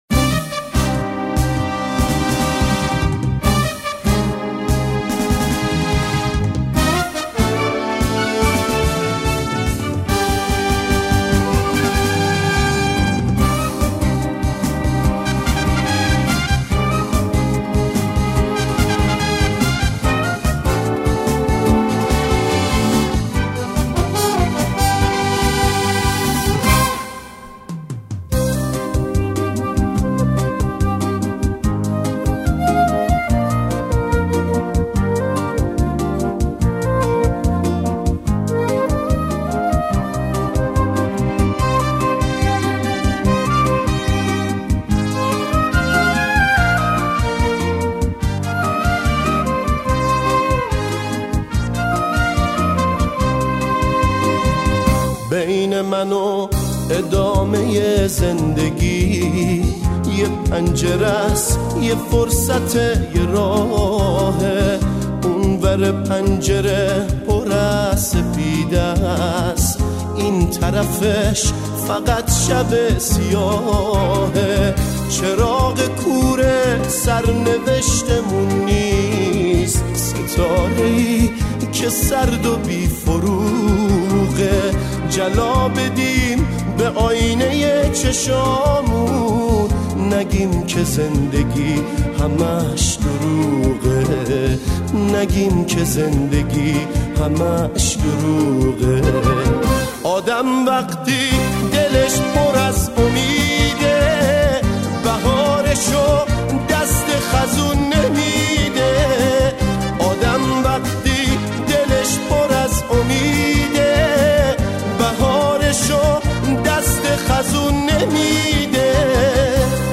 Суруди